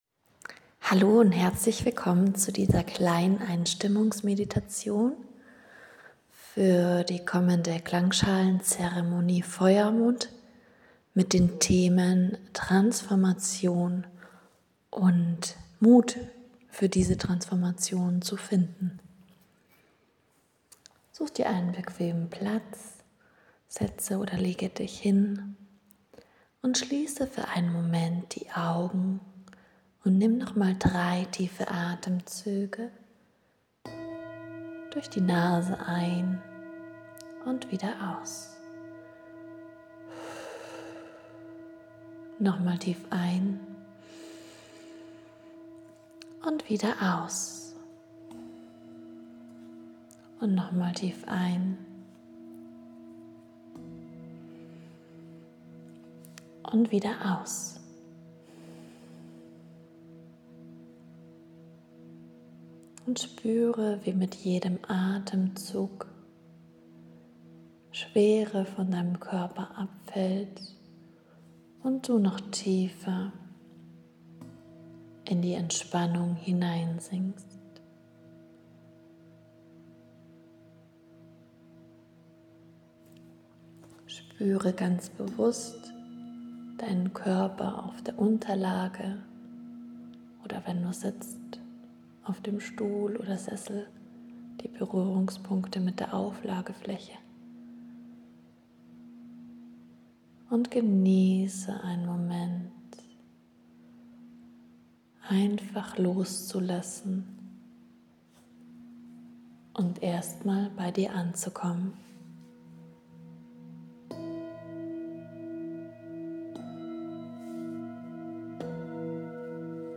Feuermond-Klangschalenzeremonie.m4a